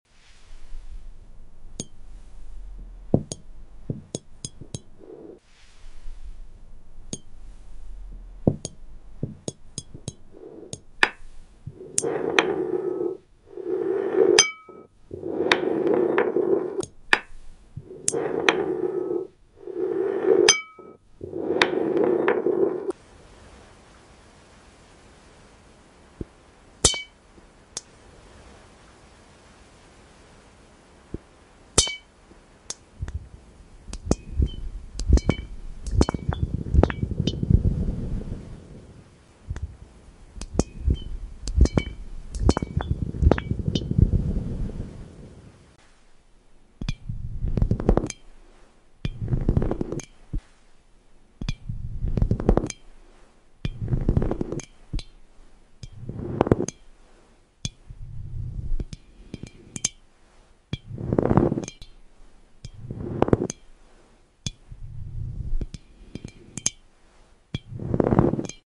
Enter the soothing world of glass ball ASMR ✨ Crisp taps, gentle rolls, and shimmering sounds that melt away stress. A hypnotic 3-scene journey into pure relaxation.